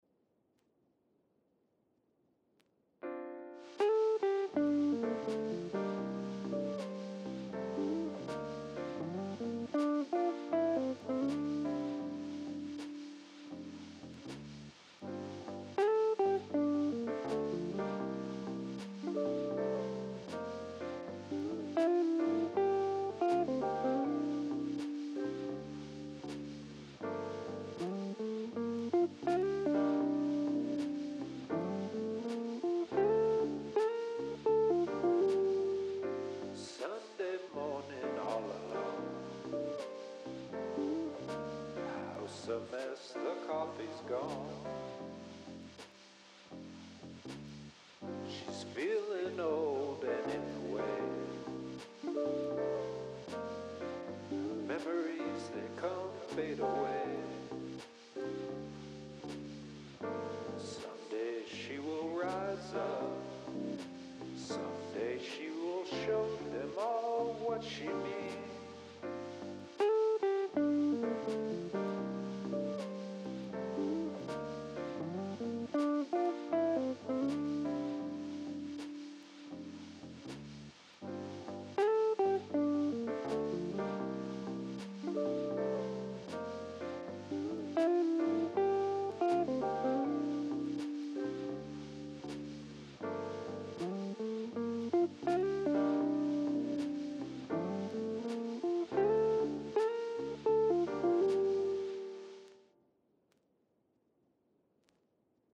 A short tune written in a Jazz Standard style.